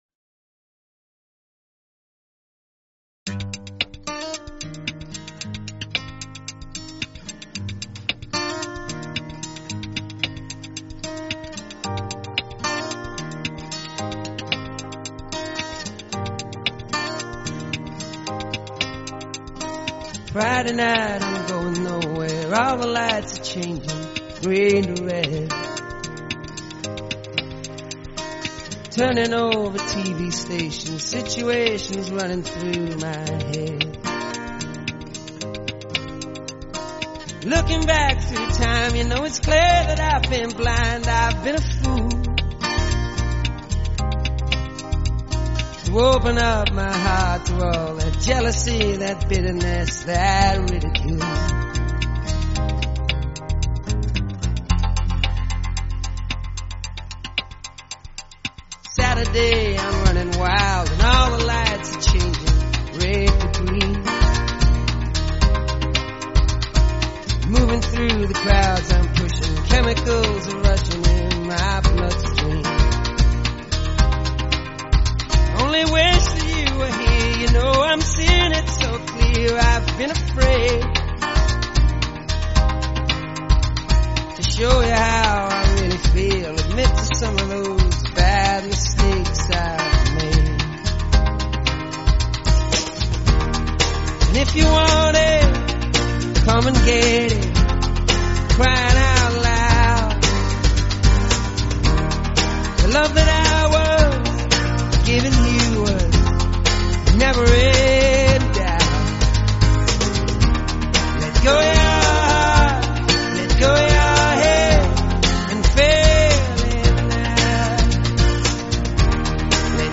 The interview offered a meaningful platform to discuss the emotional toll of long-term treatment, the burden of stigma, and the critical role of community and support networks.